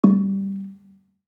Gamelan Sound Bank
Gambang-G2-f.wav